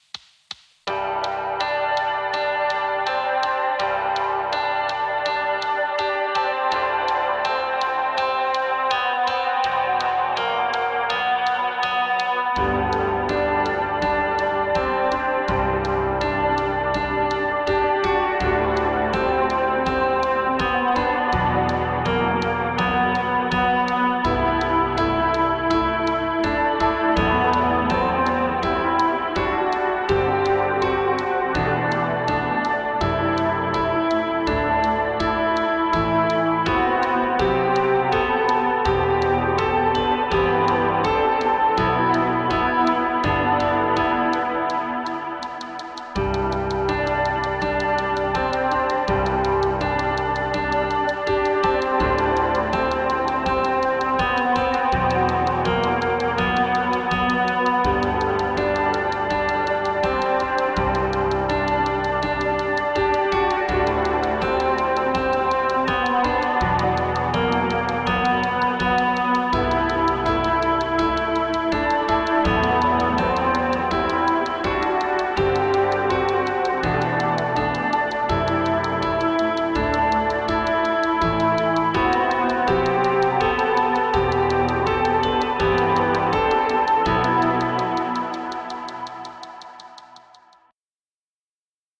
アップテンポニューエイジ明るい